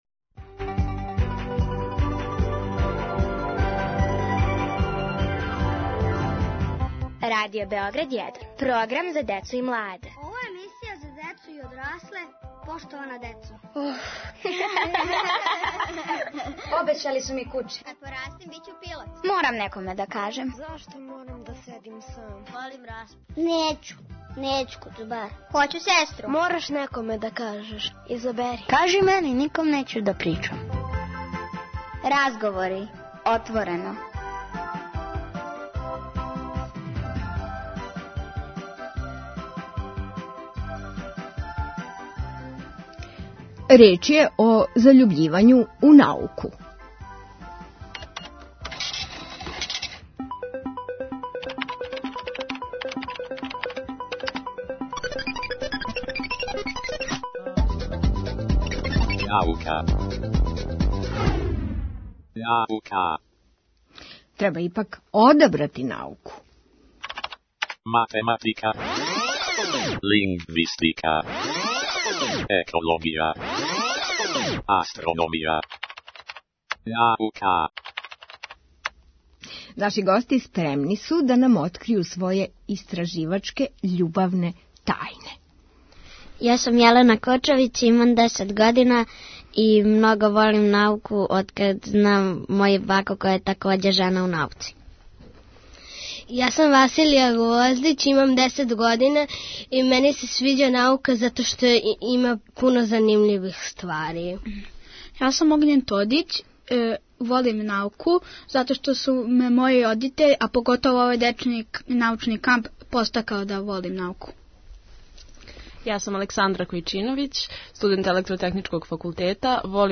У емисији разговори - поштована деца и поштовани одрасли отворено разговарају о заљубљивању у науку.